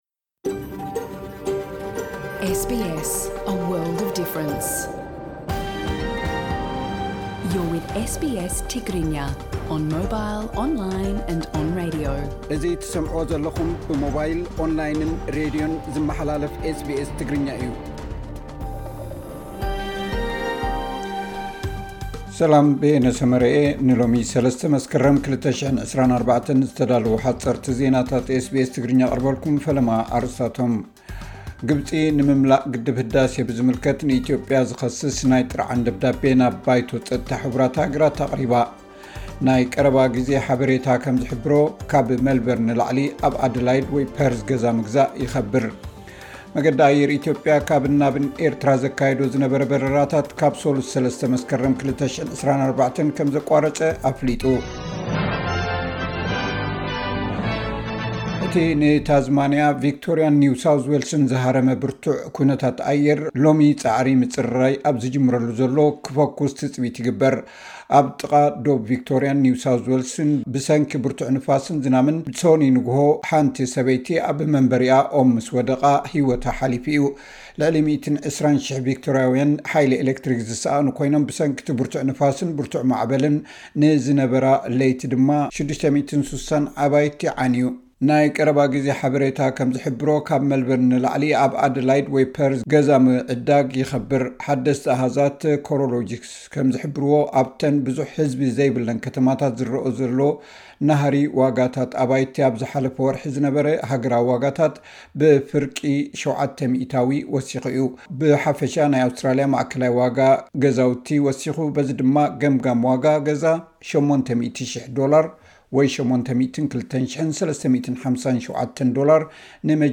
ግብጺ ንኢትዮጵያ ትኸስስ፡ መገዲ ኣየር ኢትዮጵያ ናብ ኣስመራ ምጋሽ ኣቋሪጹ፡ (ሓጸርቲ ዜናታት SBS ትግርኛ 3 መስከረም 2024)